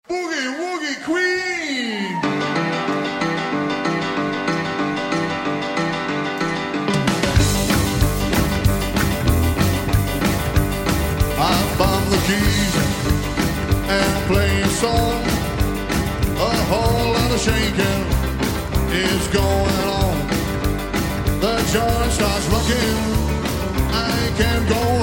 RnR, tempo 173